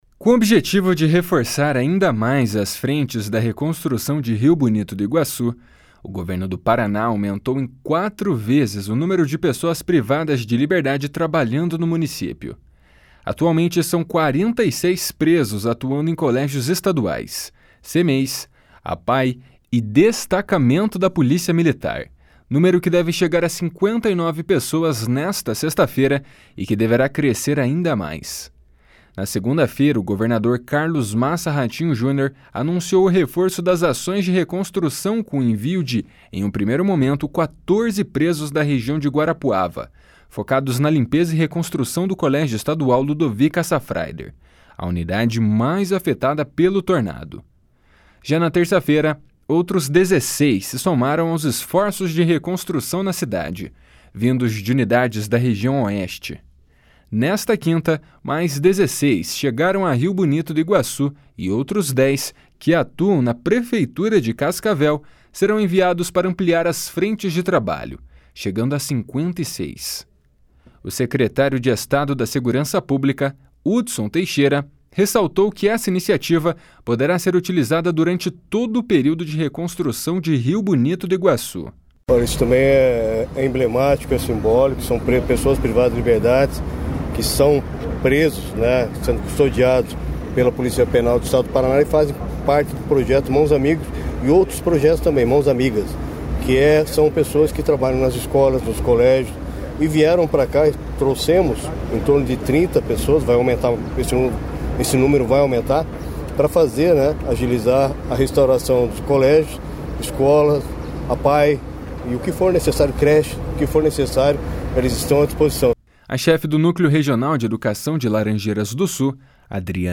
O secretário de Estado da Segurança Pública, Hudson Teixeira, ressaltou que essa iniciativa poderá ser utilizada durante todo o período de reconstrução de Rio Bonito do Iguaçu.
Repórter